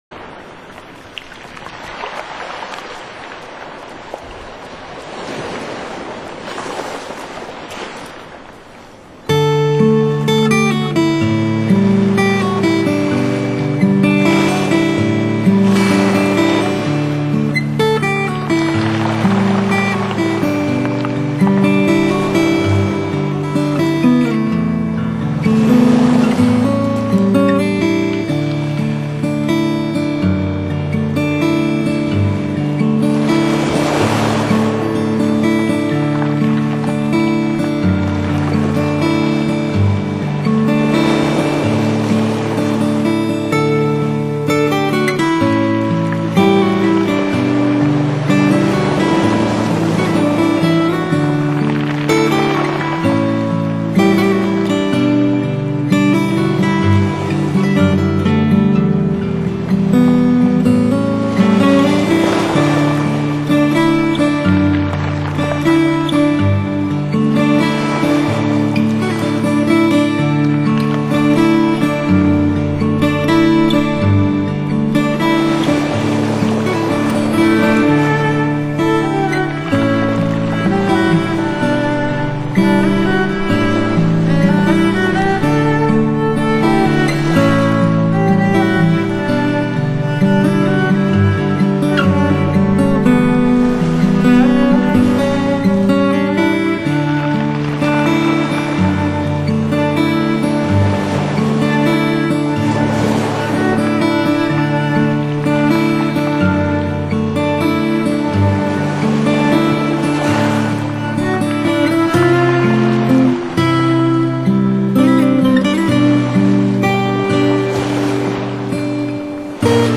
New Age、轻爵士、佛朗明哥吉他、古典钢琴小品、民谣风…10首音乐10种风格，实在好听的创作曲献给天下知音人。
大提琴
吉他带着蔚蓝的海洋气息，流进心里。
清清淡淡，即兴，自在。